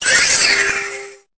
Cri de Cosmog dans Pokémon Épée et Bouclier.